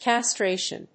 音節cas・tra・tion 発音記号・読み方
/kæstréɪʃən(米国英語), ˌkæˈstreɪʃʌn(英国英語)/